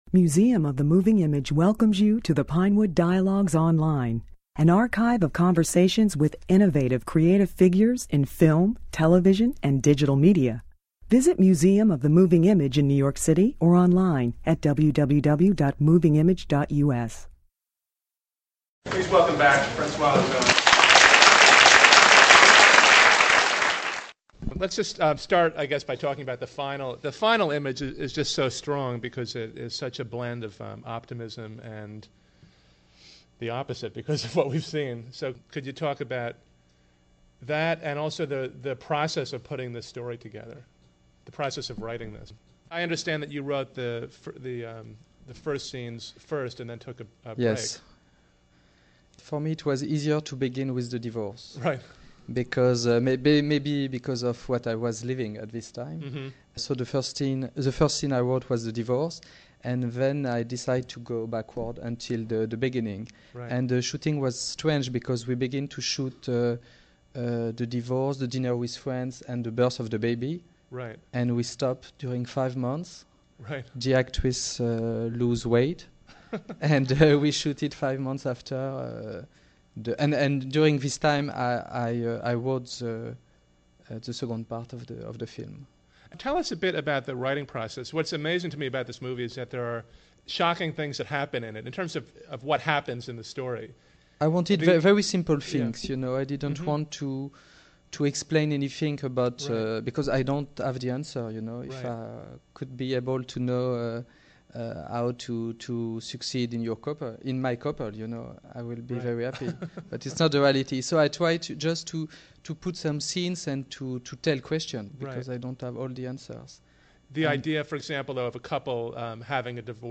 Ozon spoke at the Museum after a preview screening of his deceptively simple, profoundly haunting drama 5x2 , the story of a failed marriage told in reverse chronological order.
In the discussion, Ozon is alternately playful and serious.